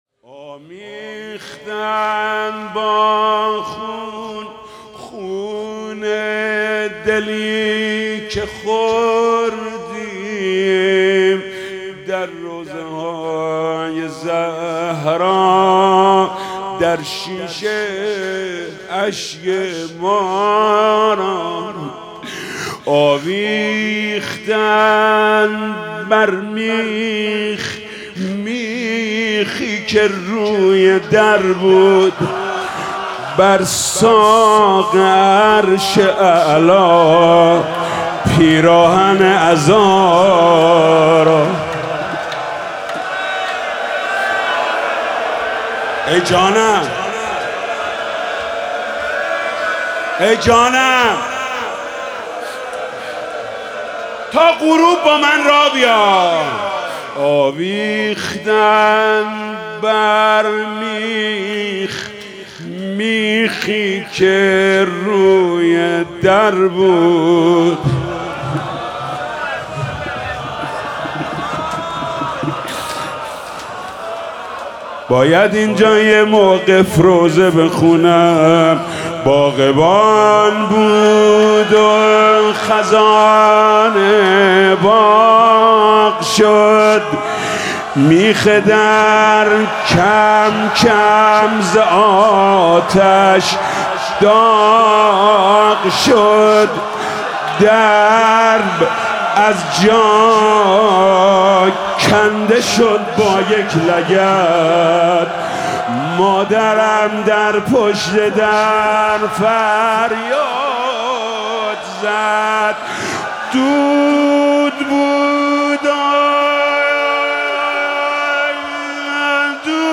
مراسم عزاداری شب اوّل محرم ۱۴۰۳ با صدای محمود کریمی
بخش اوّل - مناجات (تو عزاداری و ما برای تو، روضه می‌خونیم)